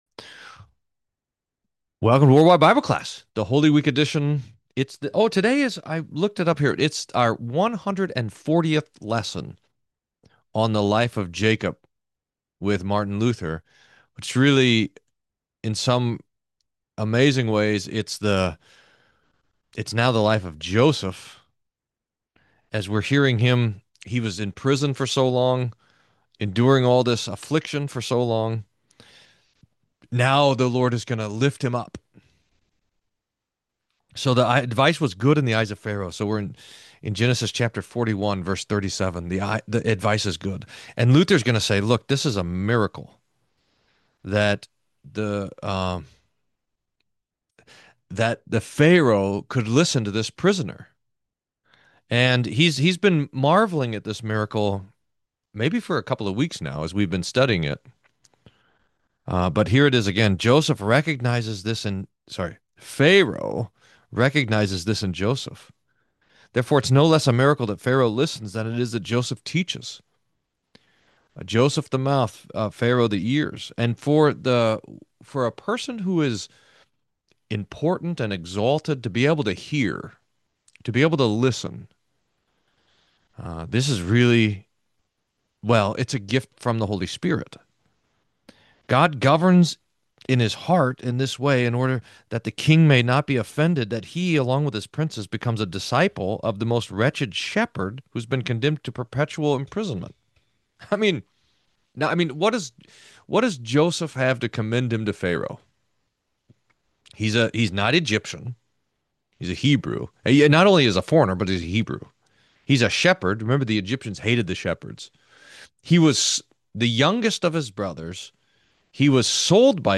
World-Wide Bible Class